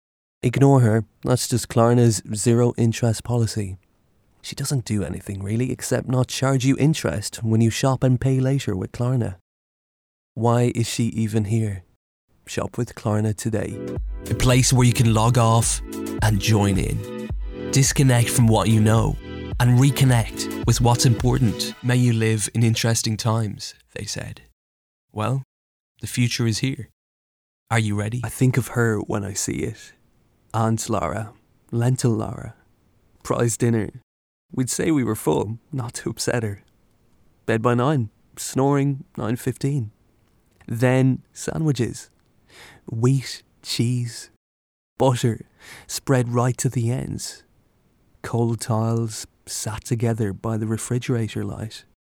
Male
English (Irish)
Yng Adult (18-29), Adult (30-50)
A talented and versatile commercial, narrative & continuity voice.
Natural Speak
Words that describe my voice are Irish, Smooth, Versatile.